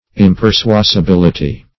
-- Im`per*sua`si*bil"i*ty , n. [1913 Webster]